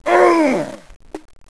zombie_growl1.wav